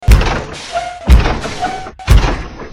Sound Effects
Large Mech Robot Steps